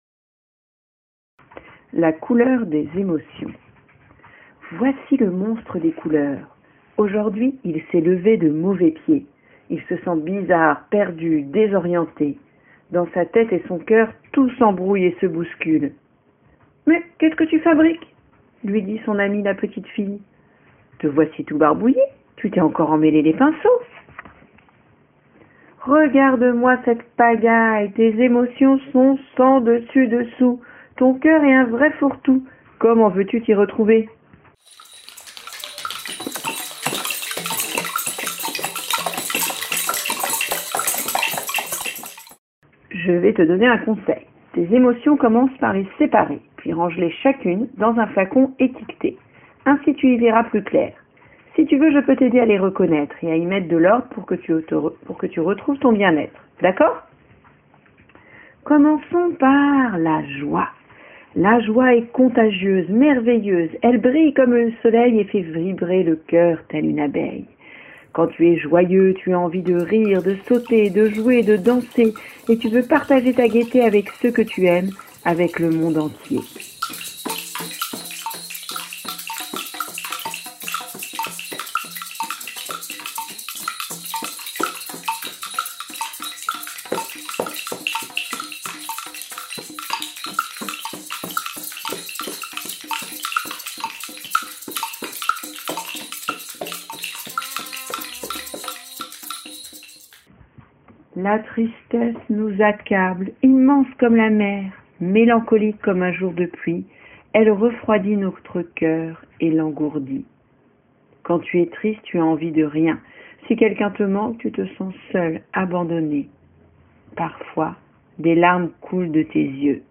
Chant & musique
Bande sonore de l’album « la couleur des émotions »